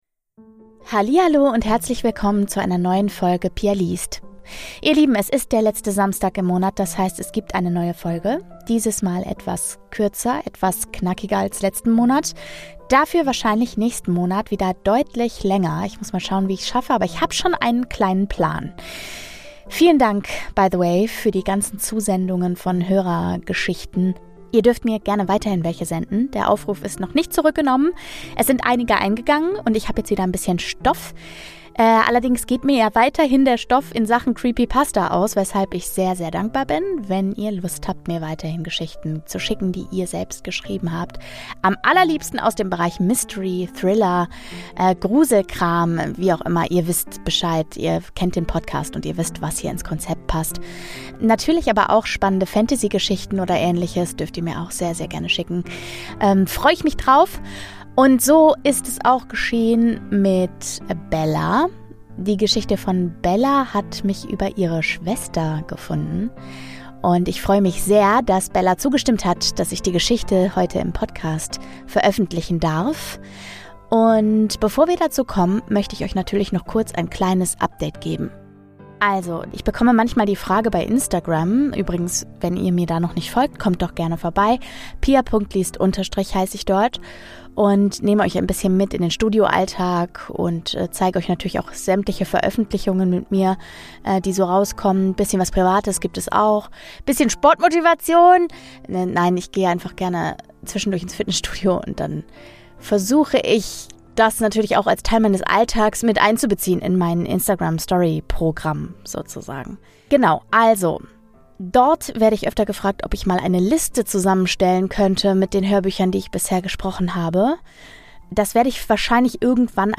Jeden letzten Samstag im Monat gibt es dabei eine meist gruselige Geschichte, die ich inzwischen mit Geräuschen, Musik und Ambient-Sounds versehe, um euch ein optimales Hör- und damit Gruselerlebnis zu geben. Dabei lese ich sowohl Creepypastas, als auch Geschichten aus dieser Community.